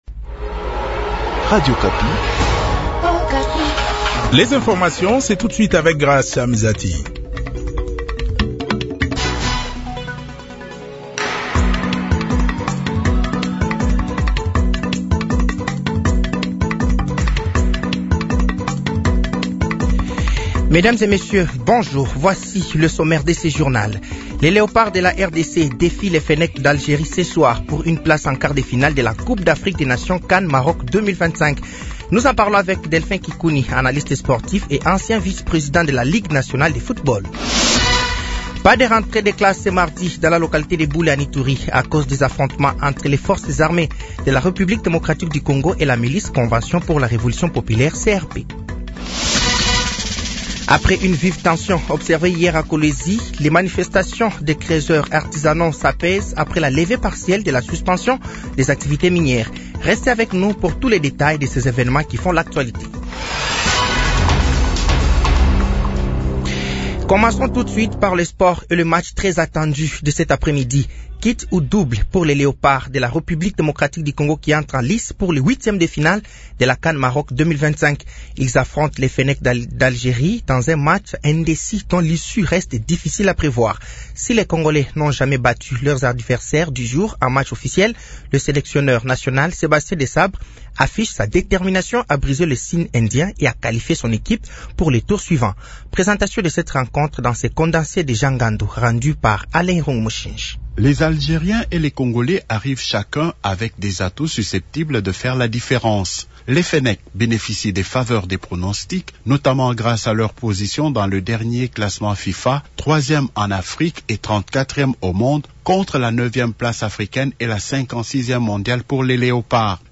Journal français de 12h de ce mardi 06 janvier 2026